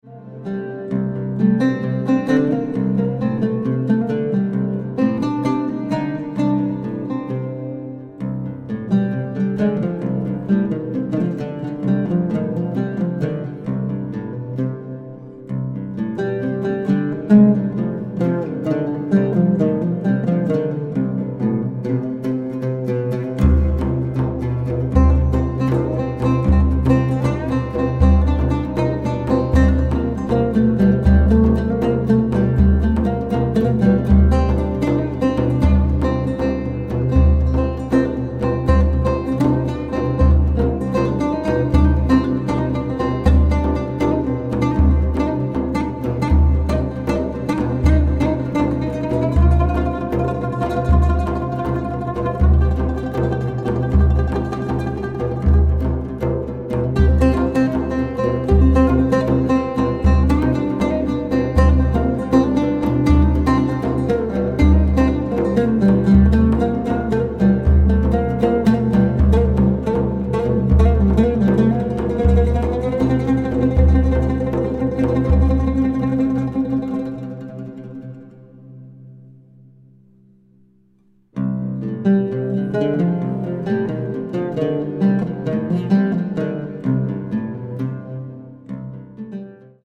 composer, lute & oud player from Japan
Contemporary
Oriental , Oud